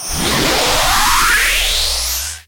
Charged Shot
A building energy charge followed by a powerful laser release with reverb tail
charged-shot.mp3